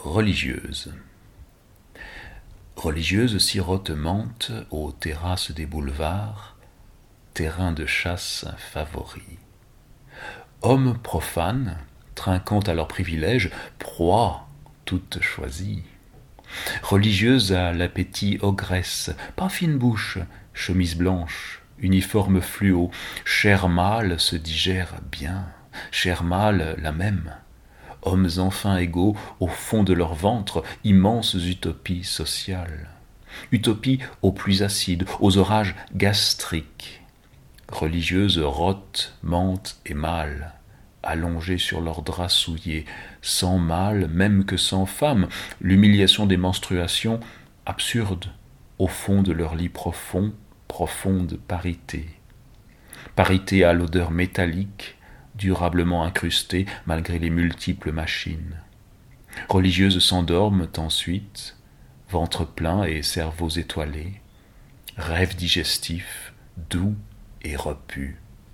lue par